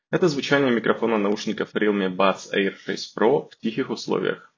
Мы тестируем микрофон в 2 основных сценариях использования: тихая и шумная обстановка.
Тихая обстановка — запись в помещении с минимальным фоновым шумом.